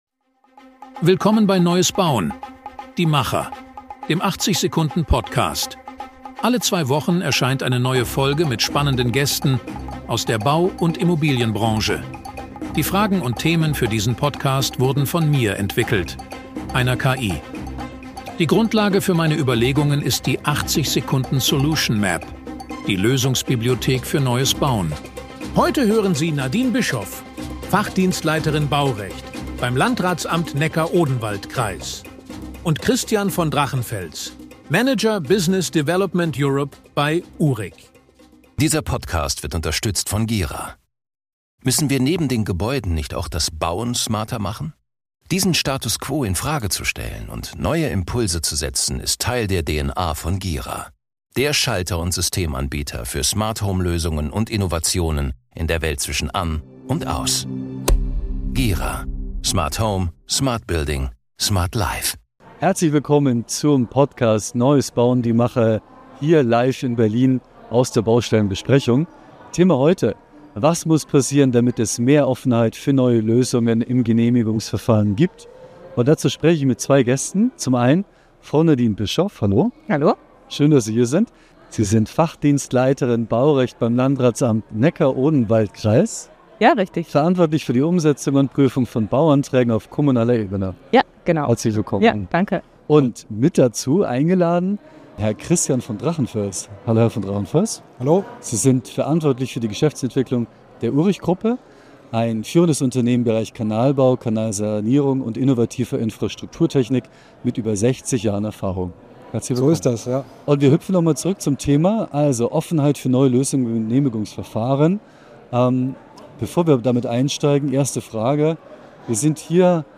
Live vom Podcast Summit / der Baustellenbesprechung in Berlin spricht die Moderation mit